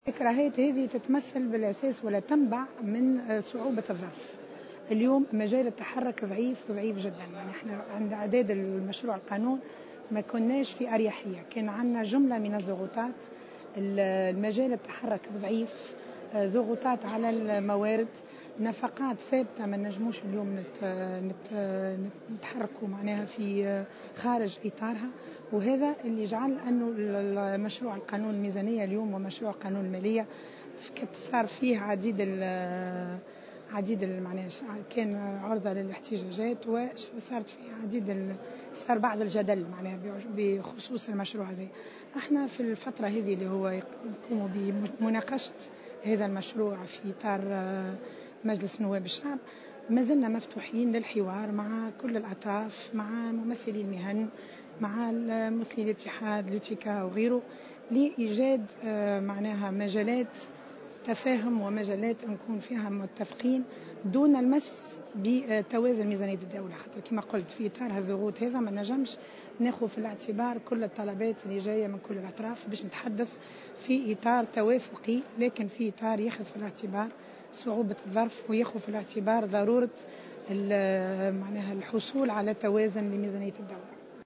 La ministre des Finances, Lami Zribi, est intervenue ce samedi 29 octobre 2016 sur les ondes de Jawhara Fm pour indiquer que l’élaboration de la Loi de finances 2017 a été effectuée dans un contexte économique difficile.